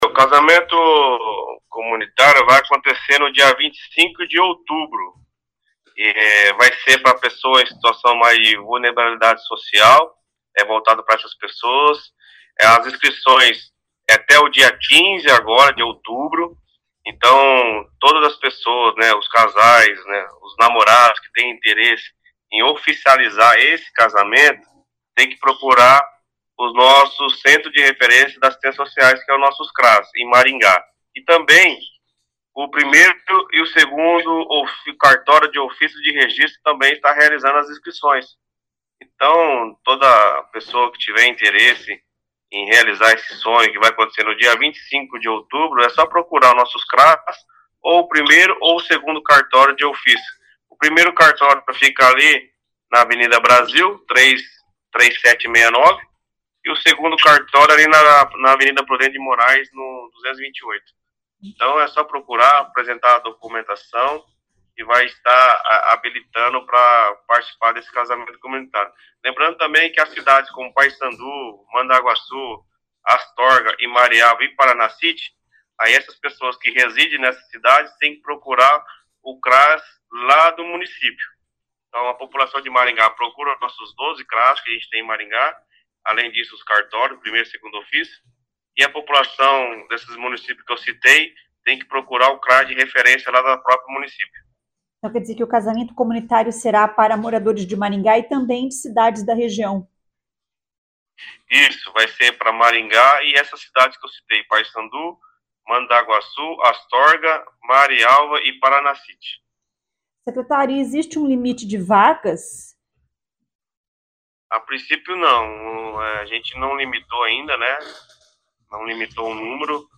Ouça o que diz sobre o assunto o secretário de Assistência Social, Leandro Bravin.